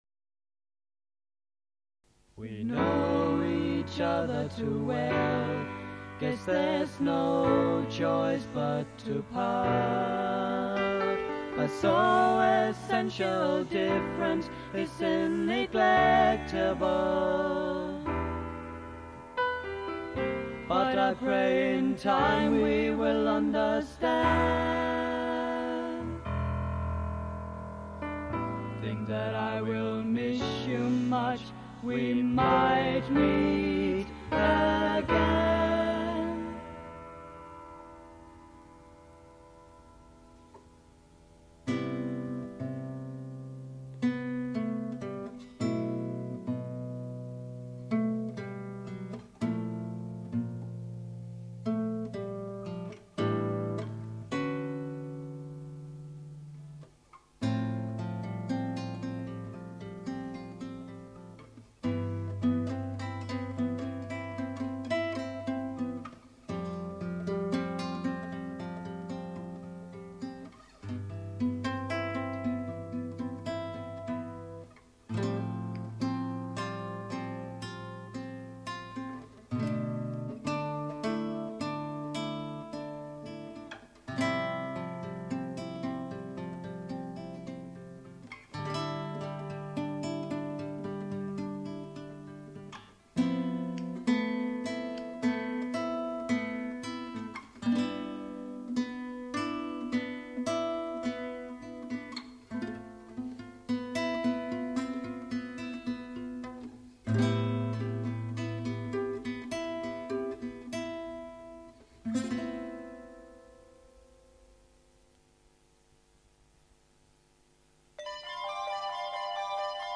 drums, percussion
guitars, bass, keyboards, percussion Demo tape
jazz piano